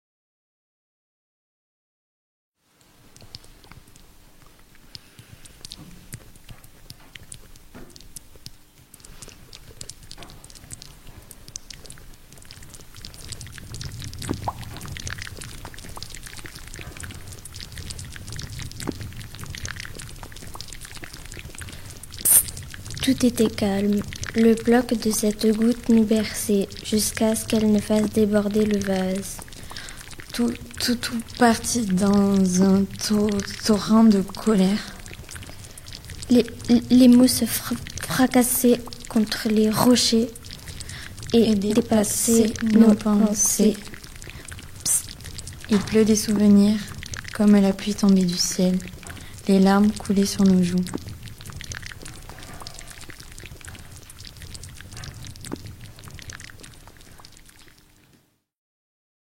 Archive 2022 : Poèmes bruités